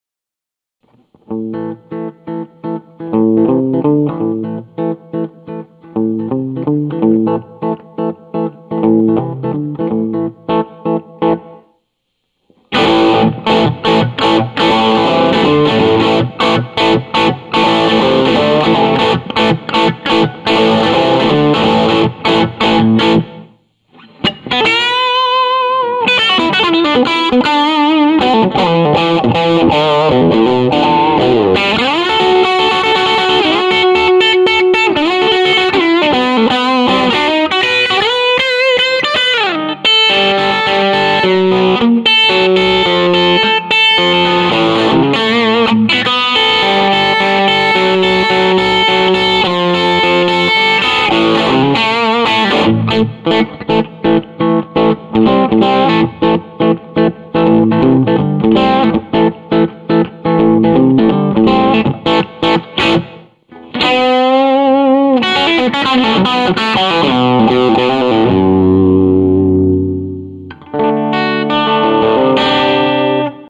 It sounds really natural.